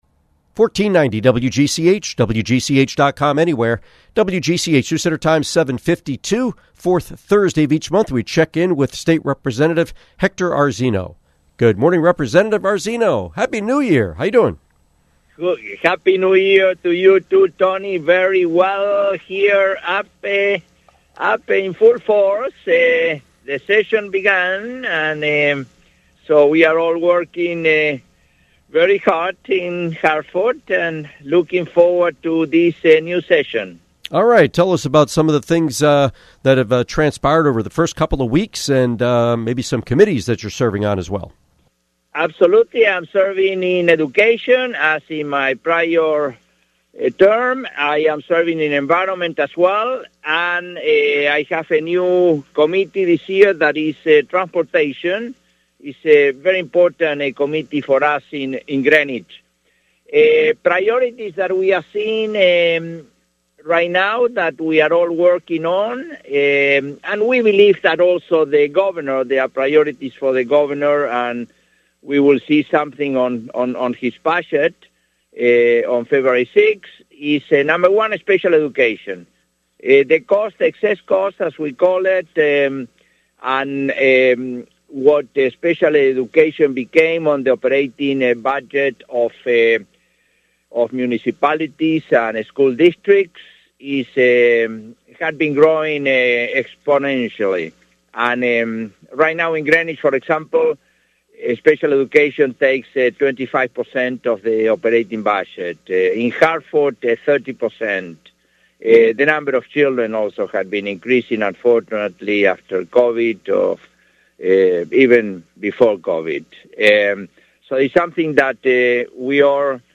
Interview with State Representative Hector Arzeno